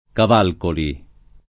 [ kav # lkoli ]